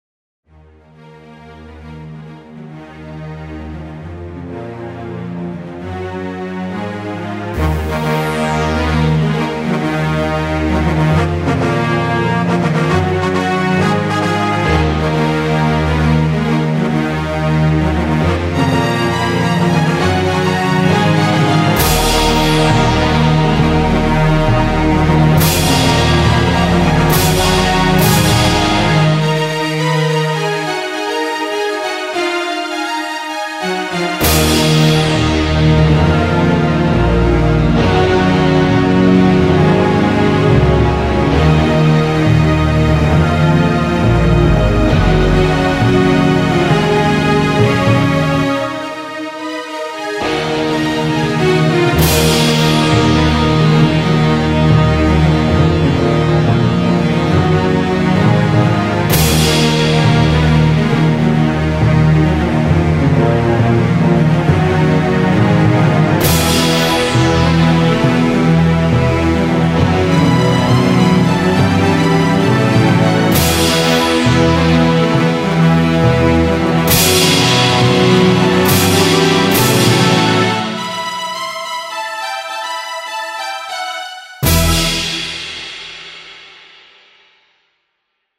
It's quite triumphant music.
Classical